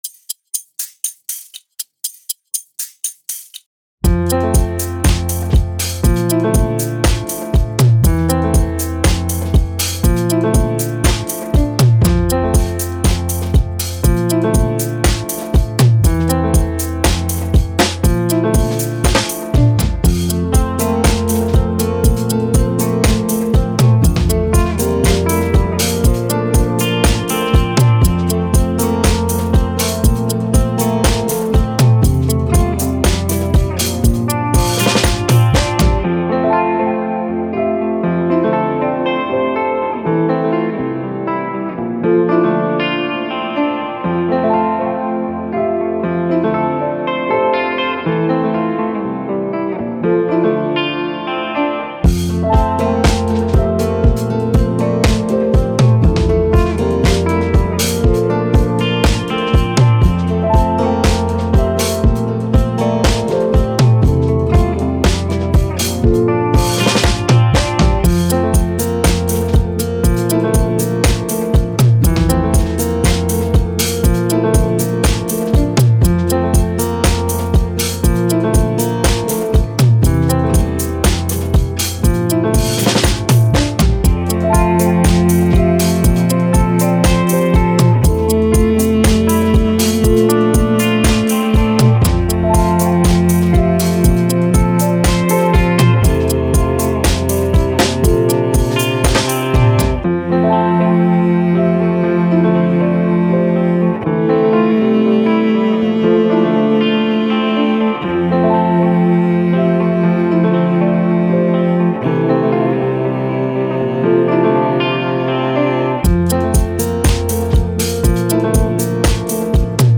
Pop, Positive, Thoughtful, Quirky, Sun